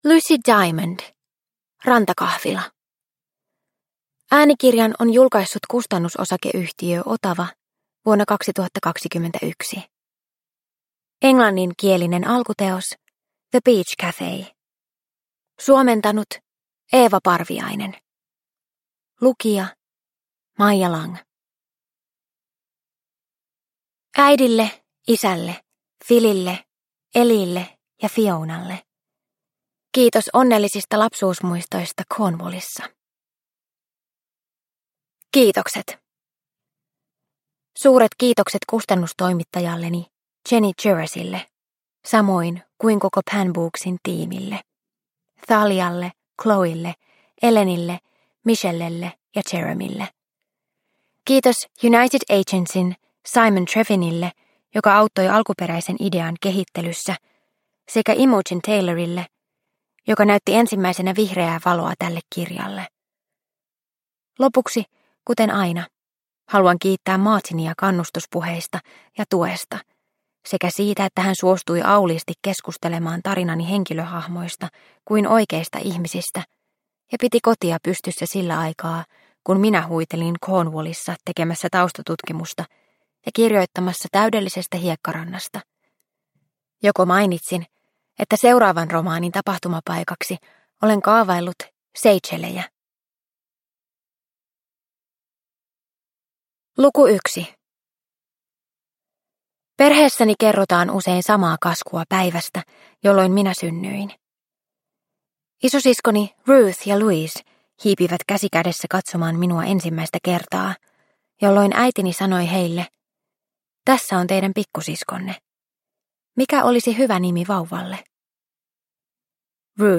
Rantakahvila – Ljudbok – Laddas ner